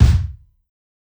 KICK_ROCKSTAR.wav